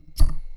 metalSlideOnMetal1.wav